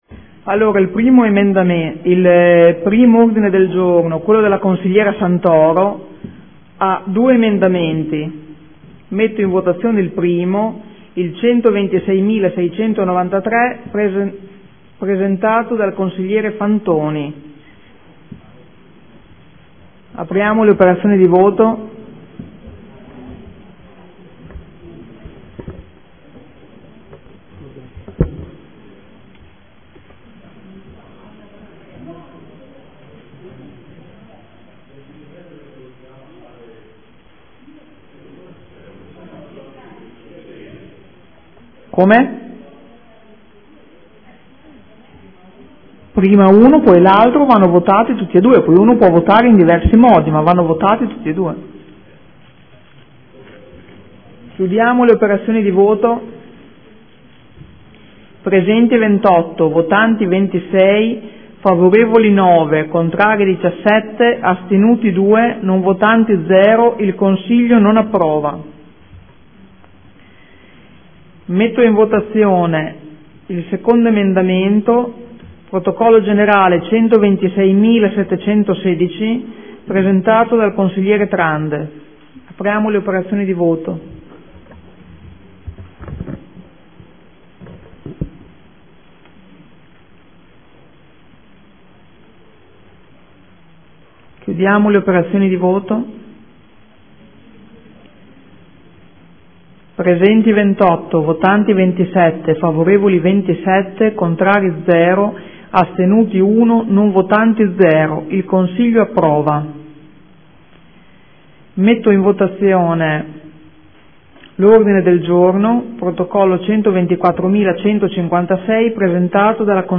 Seduta del 9/10/2014 Dibattito Sicurezza. Votazioni Odg. e chiusura Consiglio.